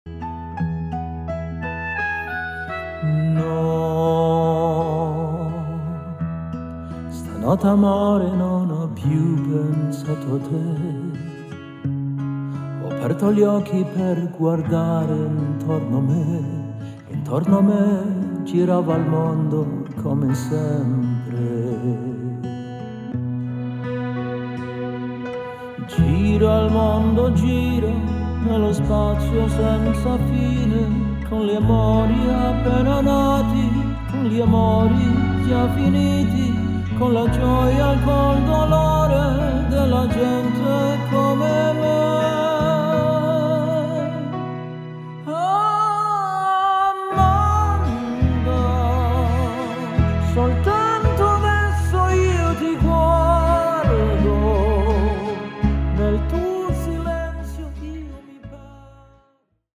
• Acapella
Vokal